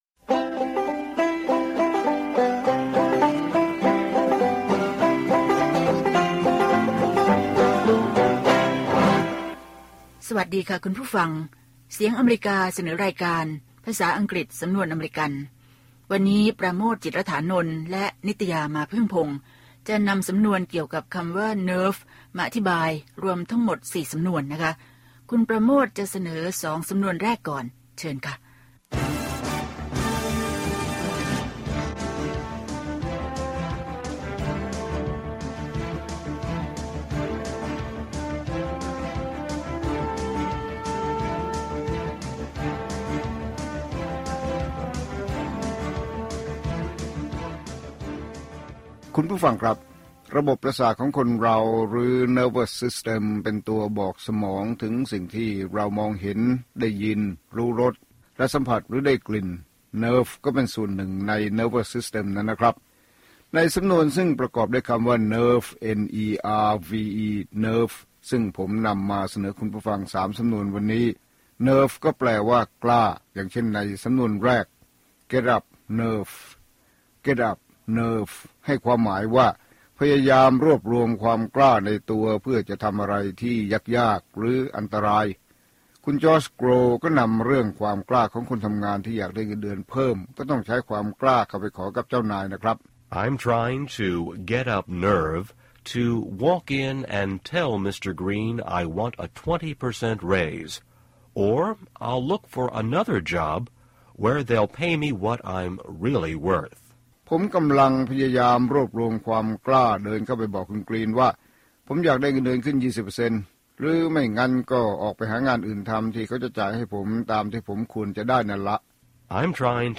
ภาษาอังกฤษสำนวนอเมริกัน สอนภาษาอังกฤษด้วยสำนวนที่คนอเมริกันใช้ มีตัวอย่างการใช้ และการออกเสียงจากผู้ใช้ภาษาโดยตรง